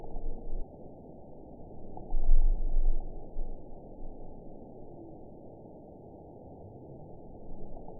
event 922529 date 01/26/25 time 03:05:31 GMT (4 months, 3 weeks ago) score 7.33 location TSS-AB06 detected by nrw target species NRW annotations +NRW Spectrogram: Frequency (kHz) vs. Time (s) audio not available .wav